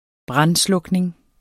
Udtale [ -ˌslɔgneŋ ]